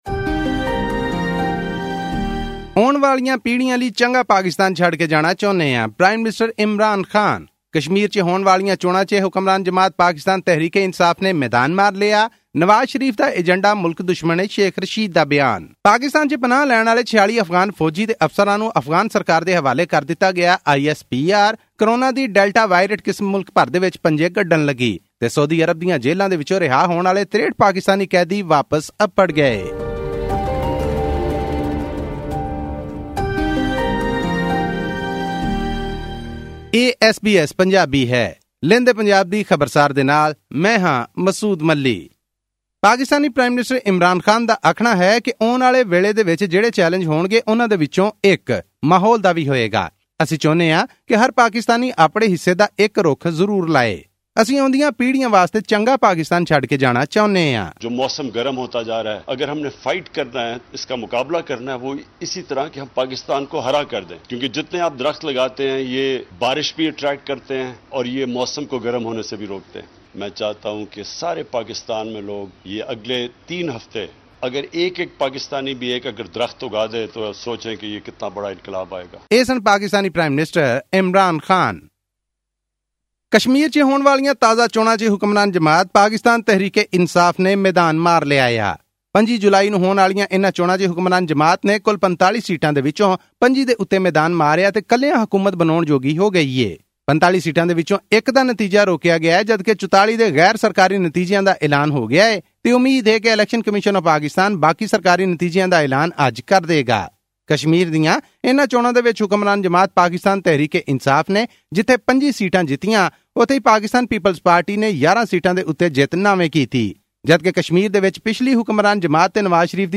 full audio report